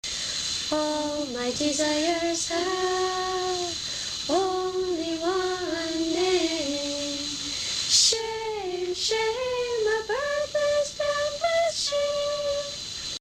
See individual song practice recordings below each score.